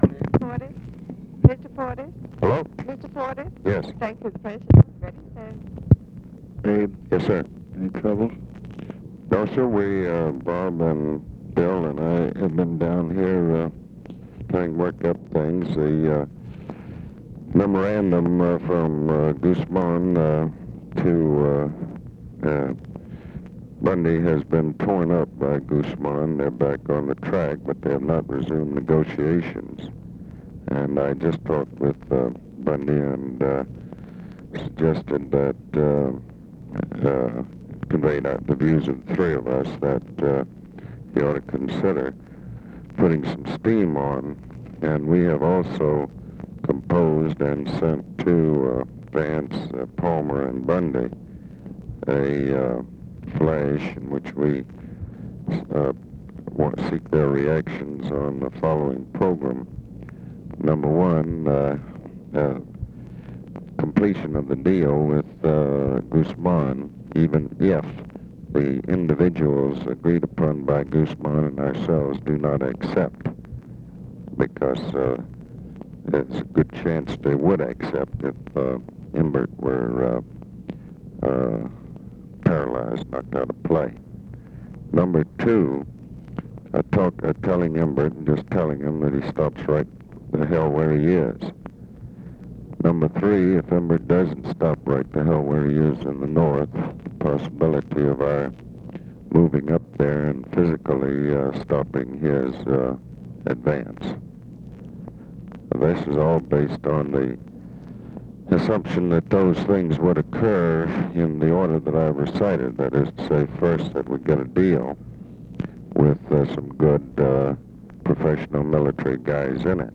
Conversation with ABE FORTAS, May 20, 1965
Secret White House Tapes